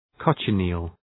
Προφορά
{‘kɒtʃə,ni:l}